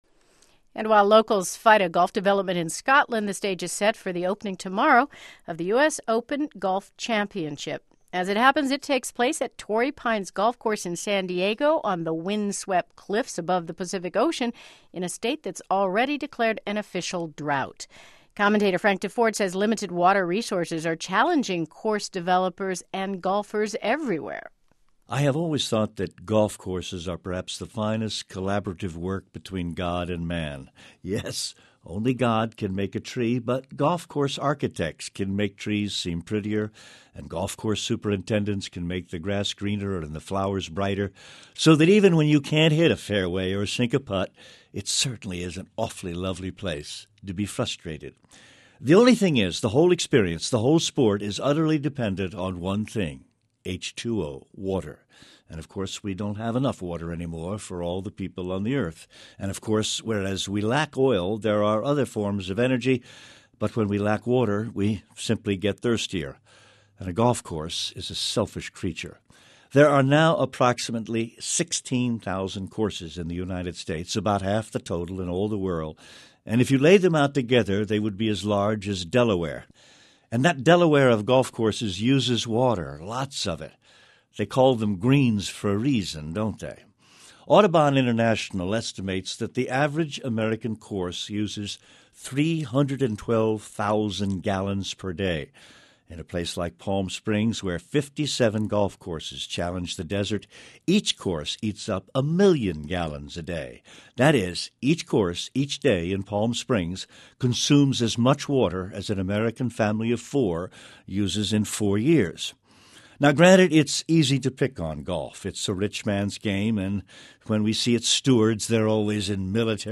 NPR Morning Edition's Frank Deford gives weekly commentary on a cross section of the world of sports.